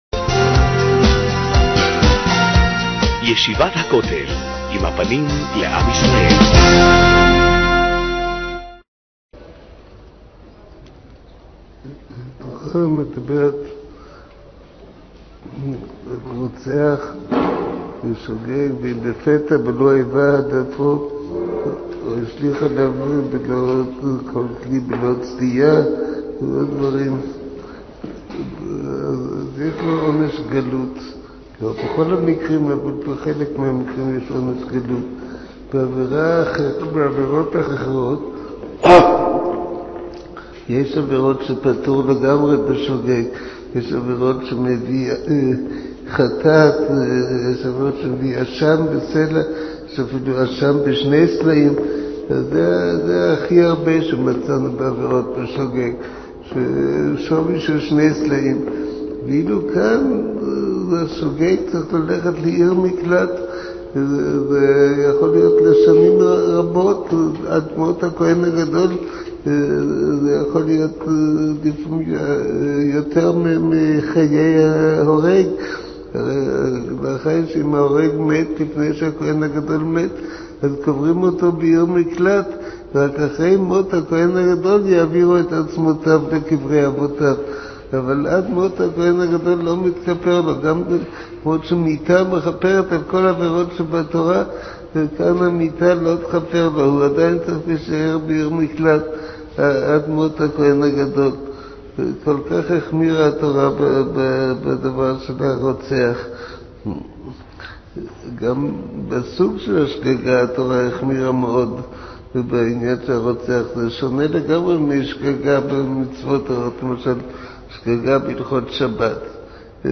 מעביר השיעור: מו"ר הרב אביגדר נבנצל